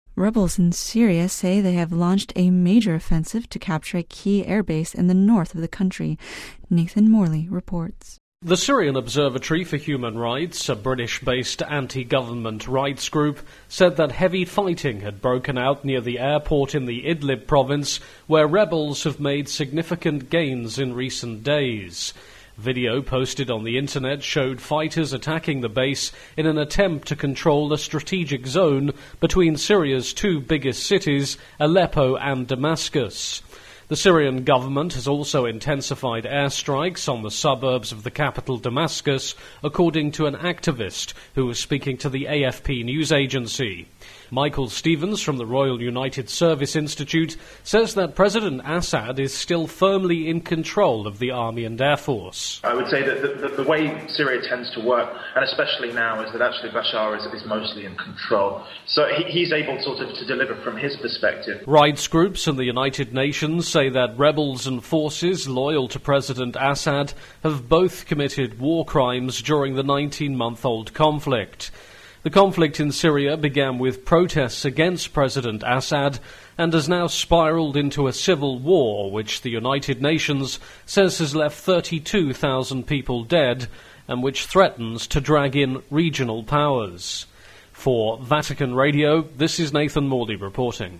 full report